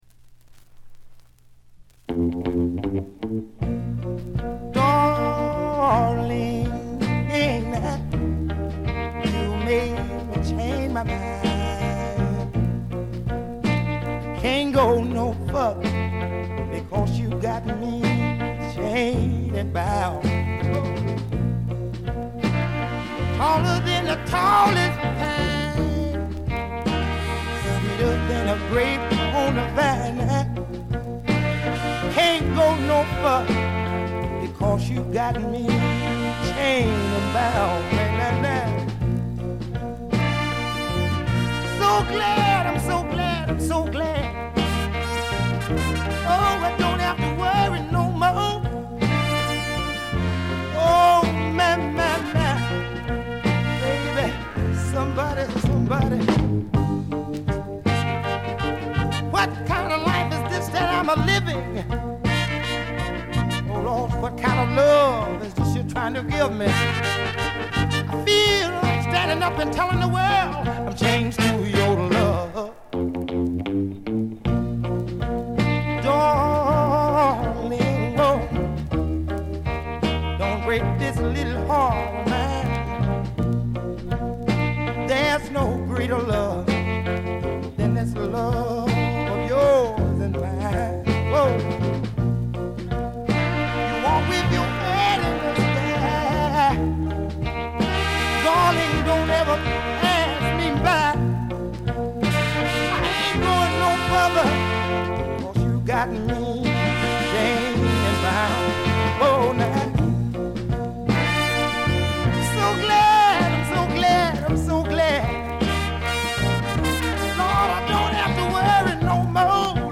静音部でのバックグラウンドノイズ程度。鑑賞を妨げるようなノイズはありません。
試聴曲は現品からの取り込み音源です。